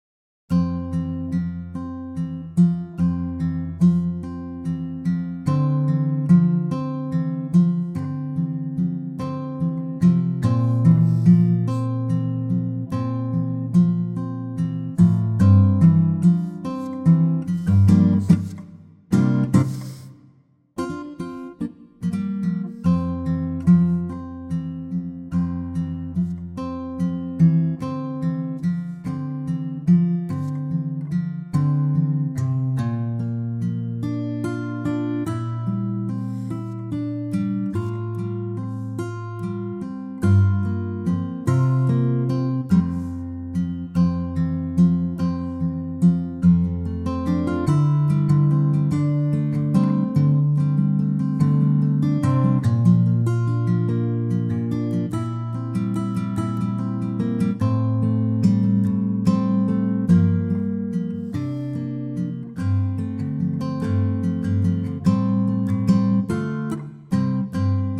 key F (key change to Ab and A for the chorus)
key - F (Ab and A for the chorus) - vocal range - A to E
with acoustic guitar only.